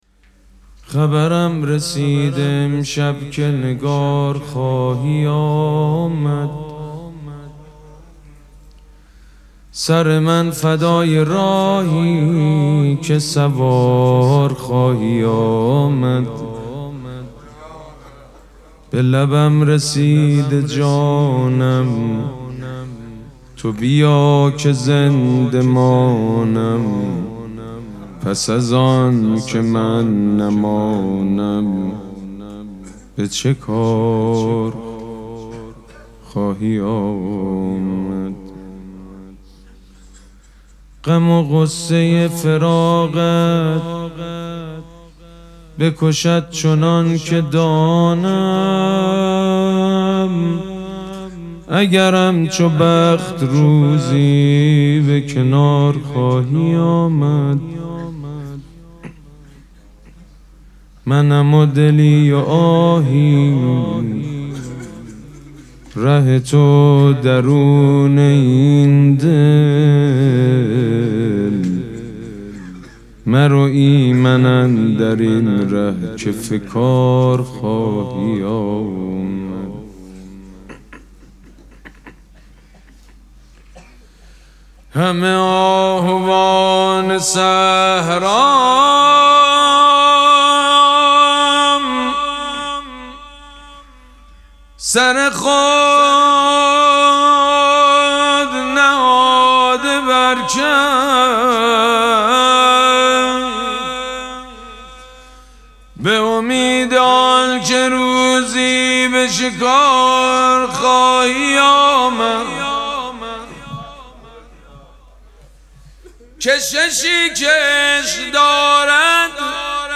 مراسم مناجات شب چهاردهم ماه مبارک رمضان
حسینیه ریحانه الحسین سلام الله علیها
شعر خوانی